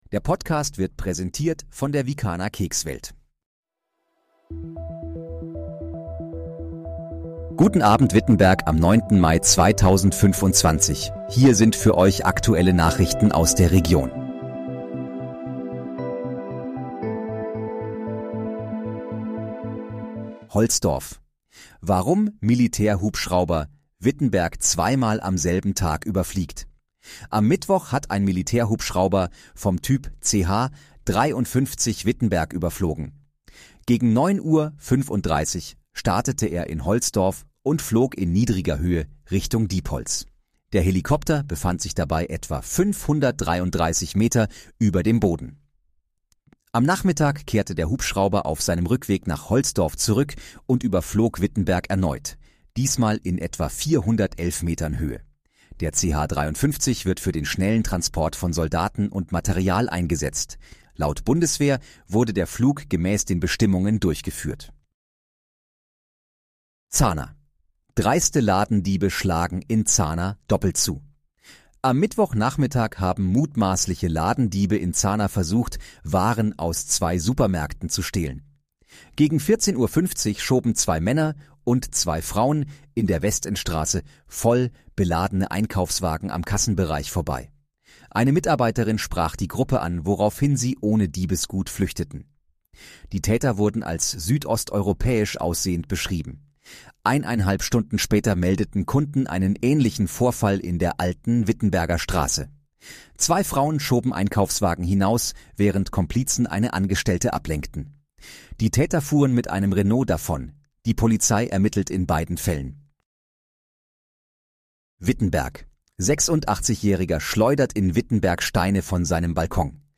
Guten Abend, Wittenberg: Aktuelle Nachrichten vom 09.05.2025, erstellt mit KI-Unterstützung
Nachrichten